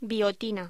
Locución: Biotina